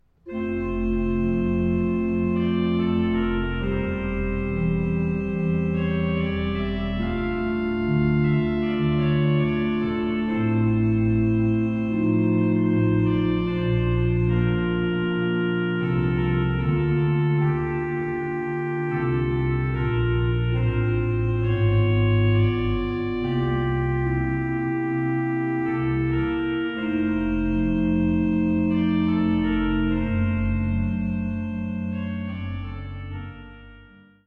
zweimanualigen Instrument